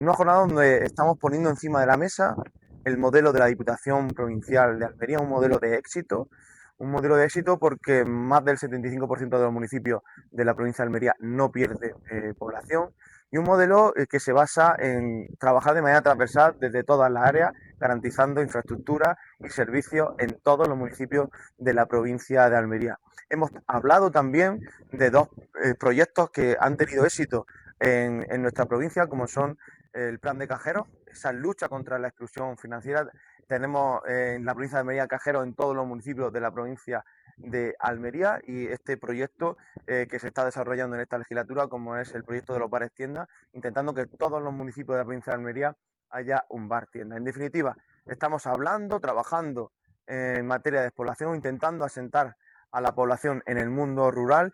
El vicepresidente, José Antonio García, participa en la jornada de ‘El Reto de la despoblación en el medio rural’, organizada por la FAMP en Segura de la Sierra, Jaén
23-10_despoblacion_diputado.mp3